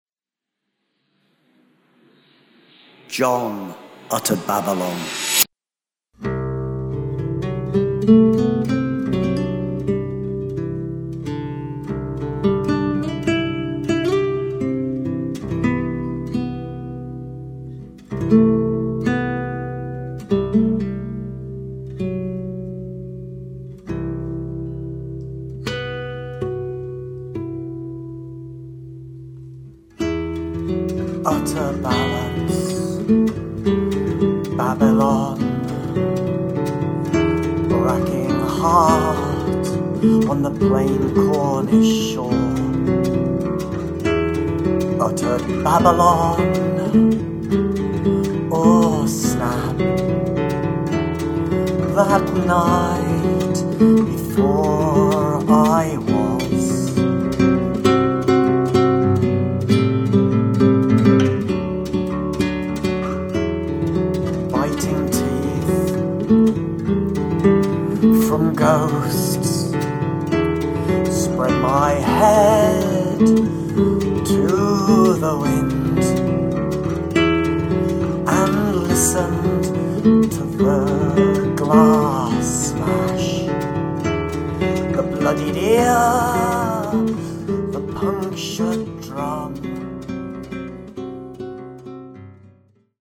their harrowing and haunted debut
classical guitar and harmonium